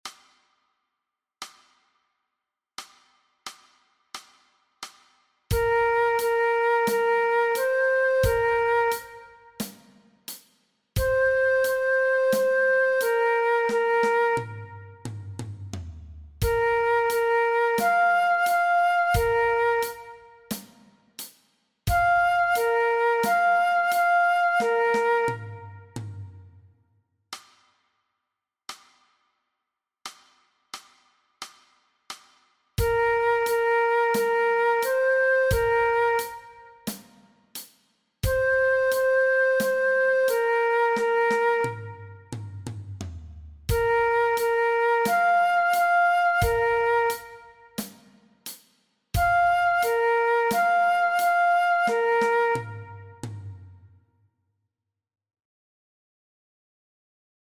Aangepaste oefening NT 1.6 Dwarsfluit
Dwarsfluit aangepast
MEESPEELTRACK-NT-1.6-Dwarsfluit-alternate.mp3